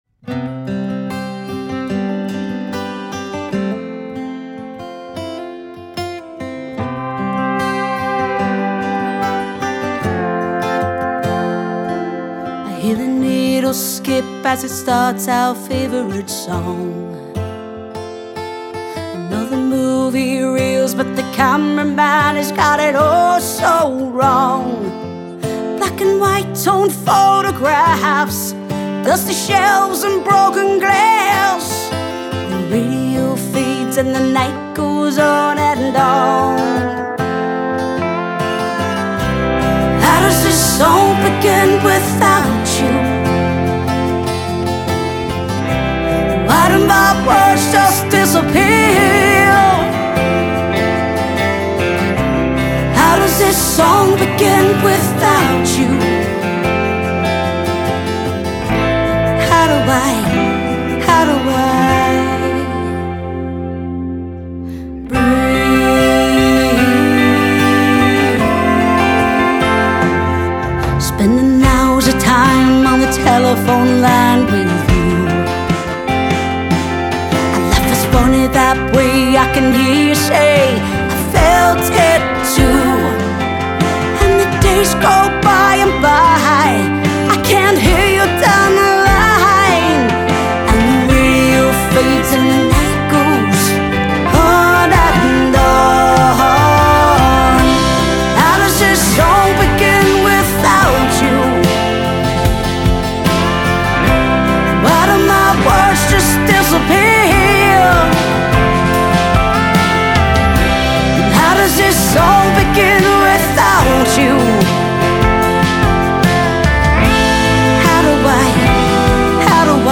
a haunting, cinematic ballad